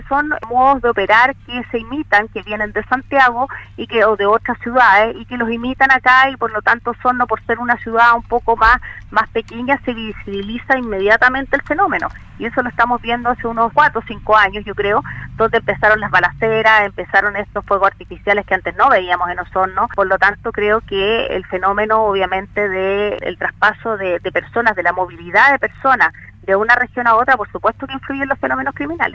La Fiscal jefa de Osorno, María Angélica De Miguel, aseguró en entrevista con Radio Sago que aún se puede anticipar y detener distintos tipos de delitos en la provincia.